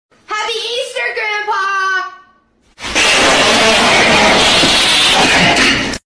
Grandpa Sound Effects MP3 Download Free - Quick Sounds